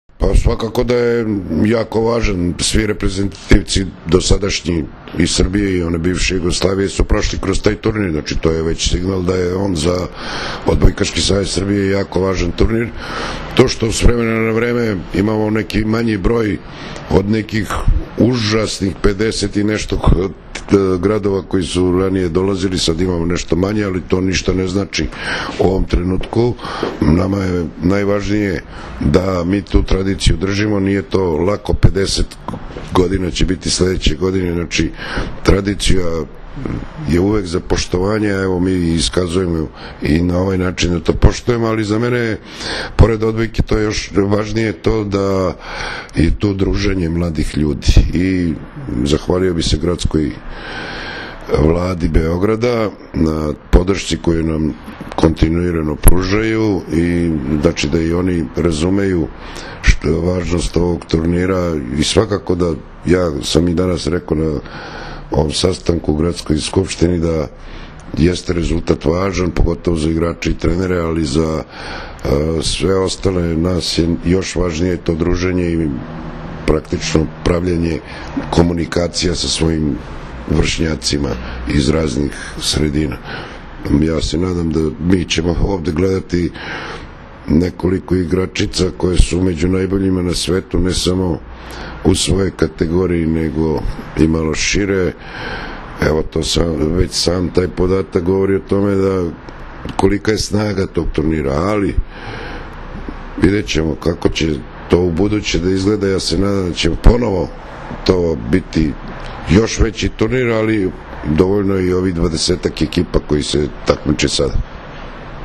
Povodom „Trofeja Beograd 2014.“ – 49. Međunarodnog turnira Gradskih omladinskih reprezentacija, koji će se odigrati od 1. – 3. maja, danas je u sali Gradske uprave Grada Beograda održana konferencija za novinare.